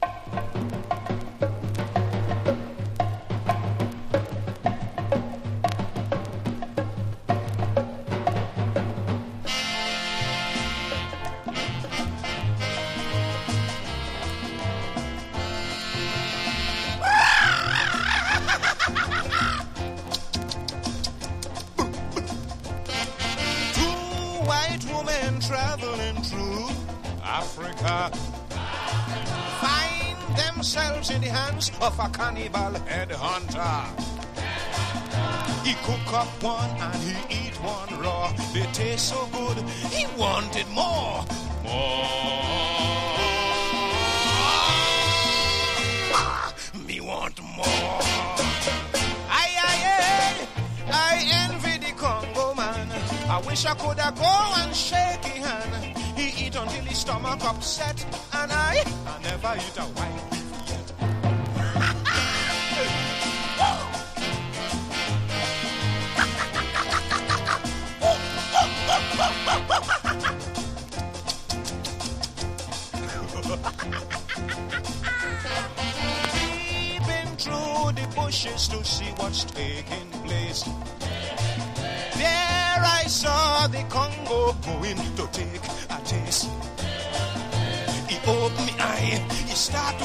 SKA / ROCK STEADY